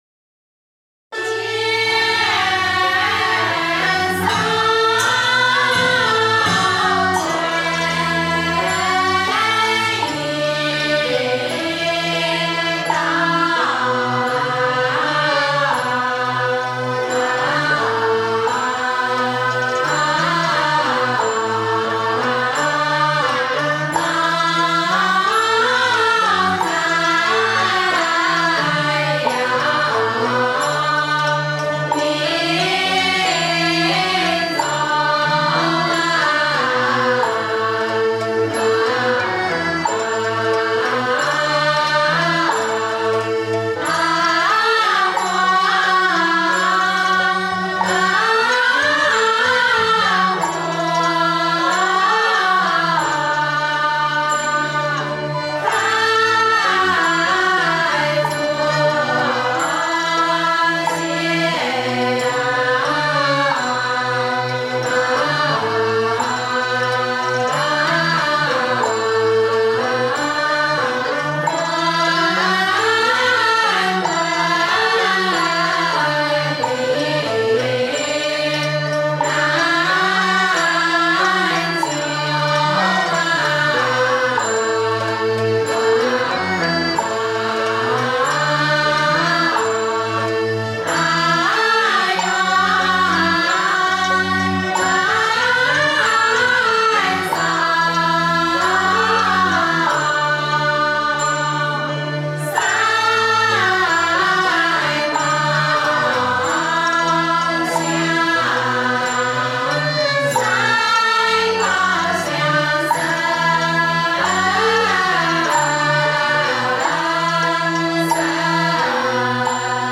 乾元观道教音乐初传，以笙、箫、管、笛、三弦、琵琶等六大件器乐为主，后经逐步演变，增配了扬琴、古筝、二胡、中胡、低音大胡等，有些器种还配备双人或四人，同时增加了系列打击乐器，形成了器乐、打击乐和声乐三大部分。
乾元观坤道仙乐内容主要分“经韵”、“曲牌”两大类：“经韵”是在斋醮活动中，以经文唱诵为主，并伴有法器敲打和乐器伴奏的韵腔；“曲牌”则是纯粹的乐器演奏，亦可添加词牌，多为寄情山水、净化人心或陶冶情操、修心养性等用。其韵腔以老道长心传口授为主，在全真十方正韵的基础上，蕴涵了江南丝竹和正一派的韵味，以及地方戏剧音乐元素，整体感觉细腻柔和、温婉清雅。